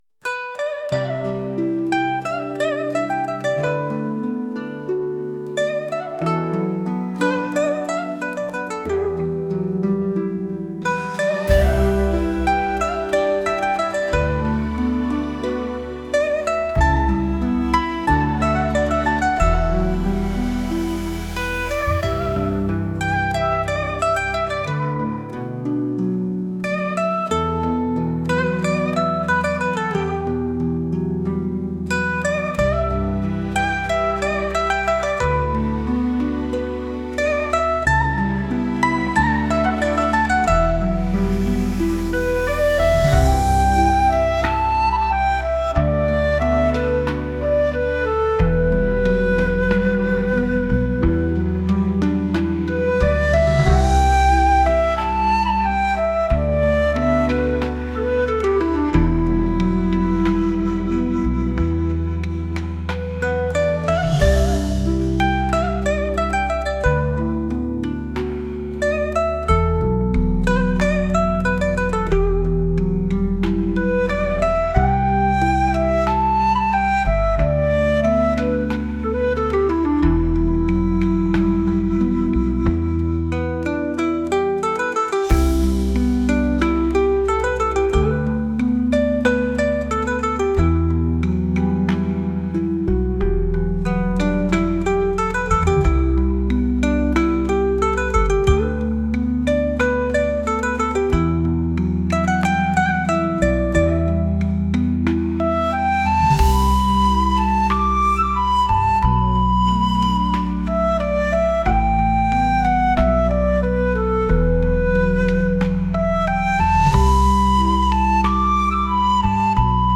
二胡を使った中華風の曲です。